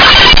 switch01.mp3